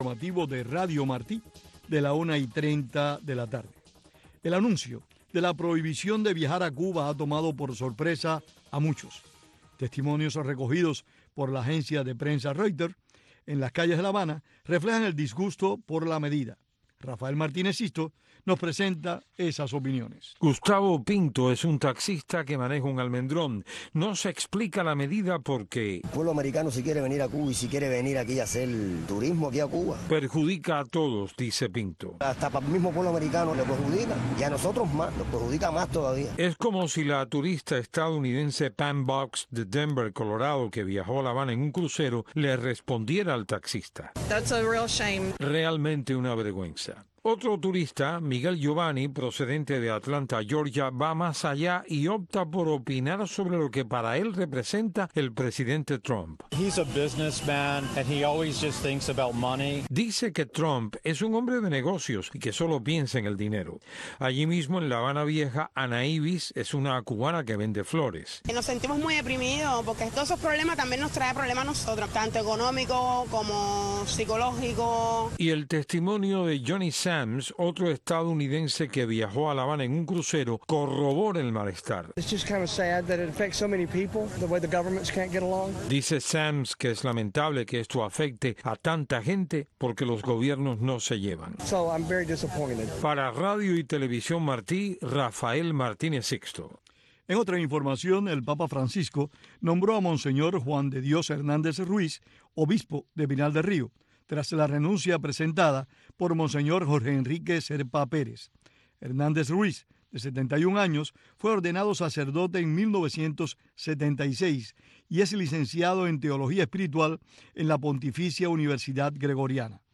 Hoy en “Ventana a Miami”, te traemos la tercera parte de la entrevista que Demetrio Pérez Jr concediera a Tomás Regalado, Director de las Oficinas de Transmisiones a Cuba.